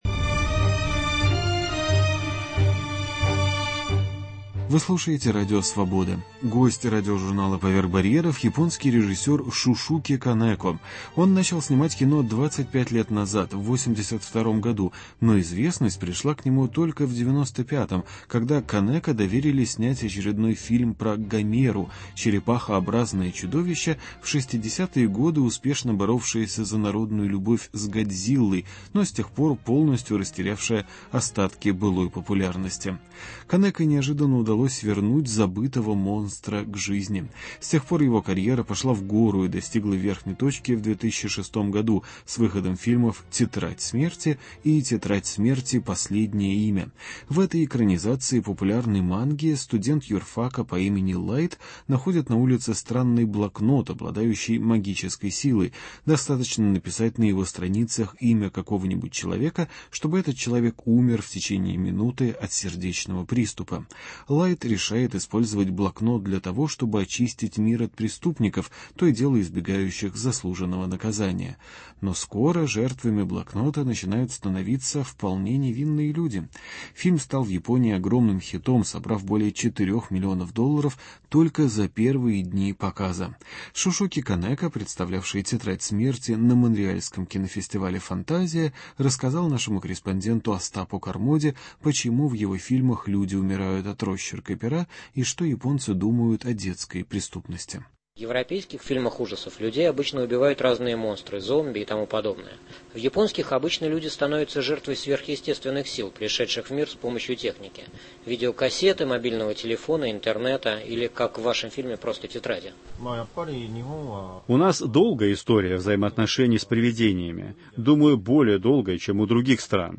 Интервью с японским кинорежиссером Шушуке Канеко